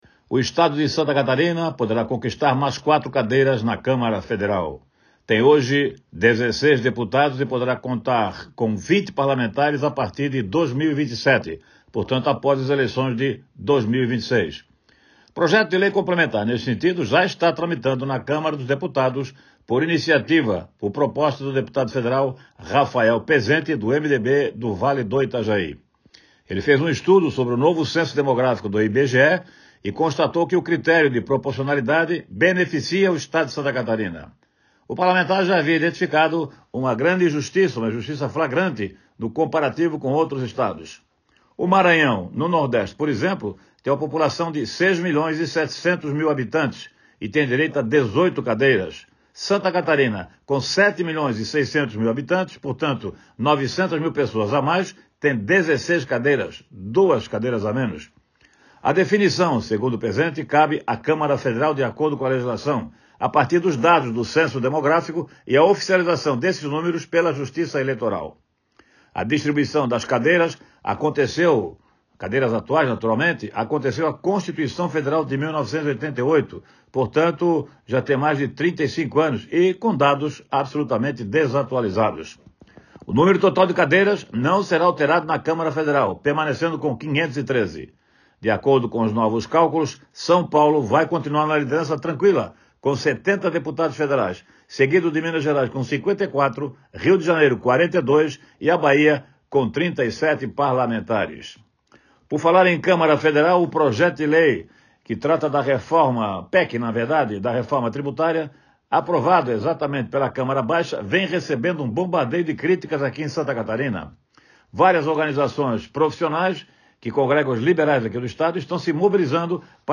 Confira o comentário na íntegra